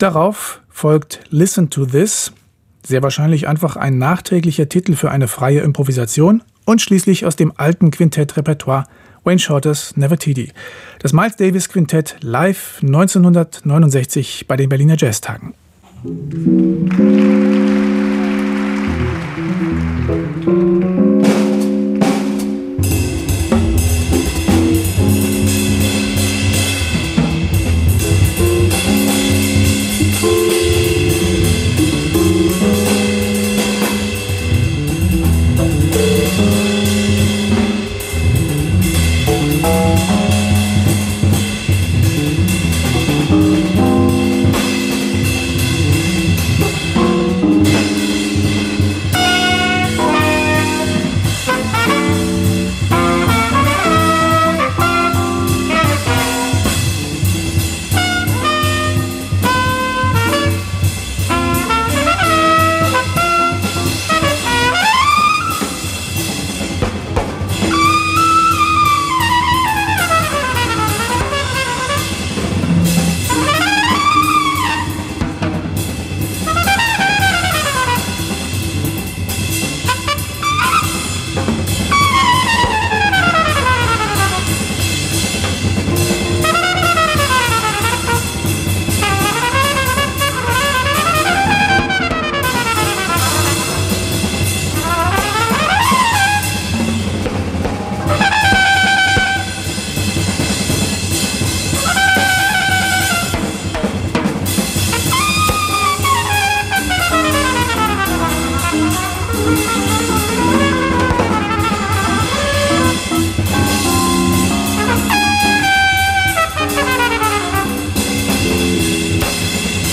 bass
piano
drums